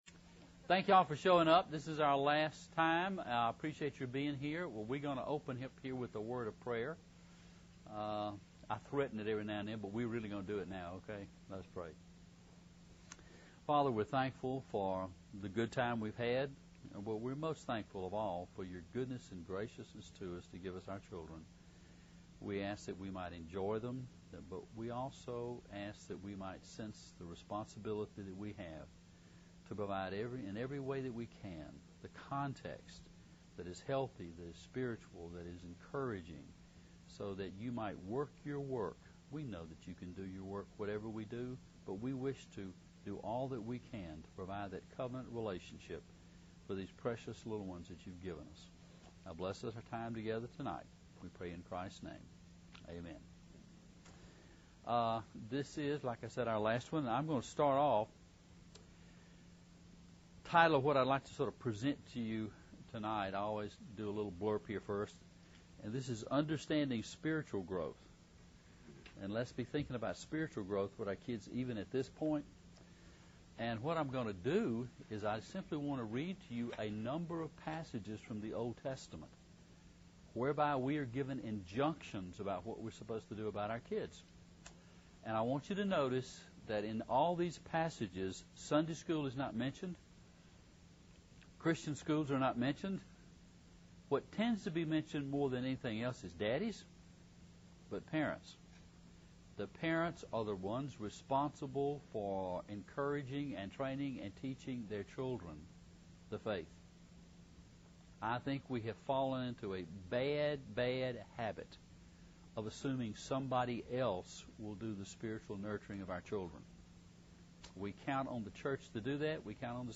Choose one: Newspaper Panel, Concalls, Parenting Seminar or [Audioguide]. Parenting Seminar